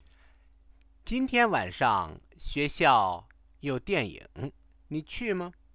Click on a sentence to hear the sentence spoken and place the mouse on a sentence to view the translation in English.